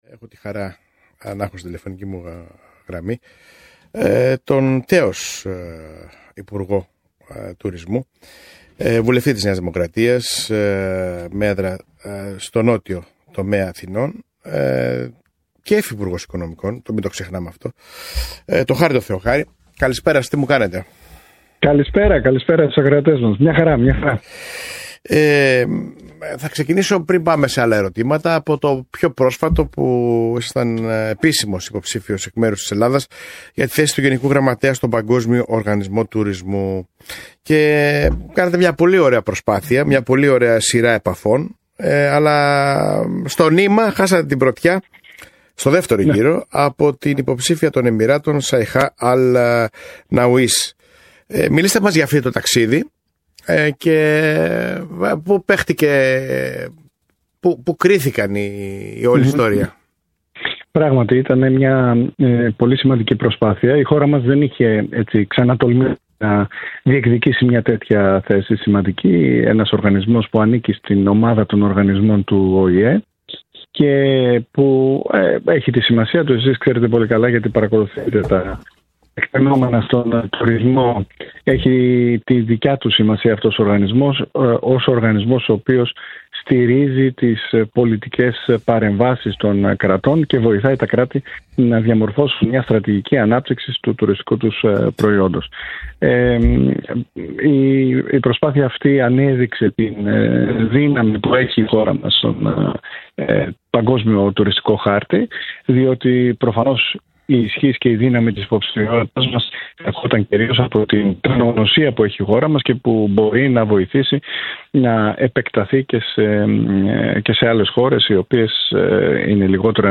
μίλησε ο Χάρης Θεοχάρης, Βουλευτής της Νέας Δημοκρατίας στον Νότιο Τομέα Αθηνών, πρώην Υπουργός Τουρισμού και πρώην Υφυπουργός Οικονομικών